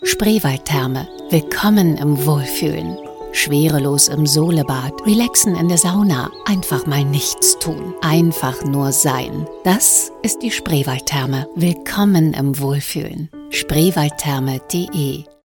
Spot - Kletter Ass